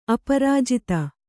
♪ aparājita